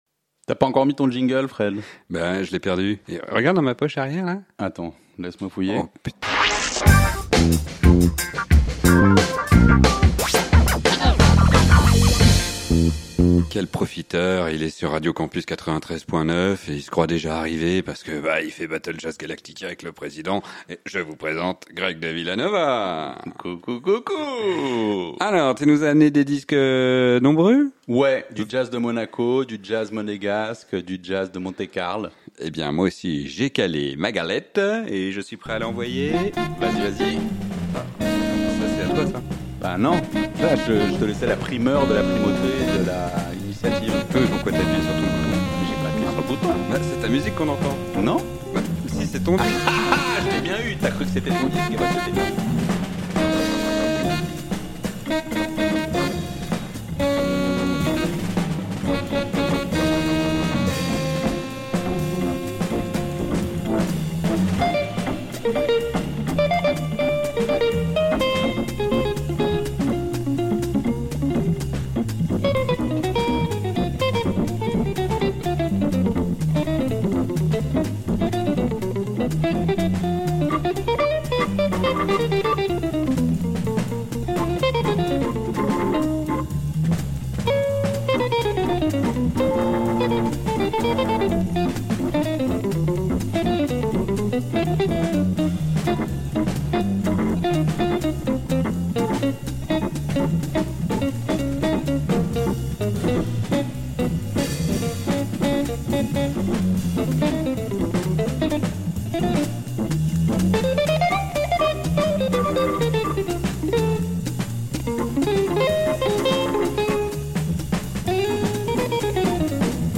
Tension.
Pas beaucoup de mots. Le crépitement du diamant.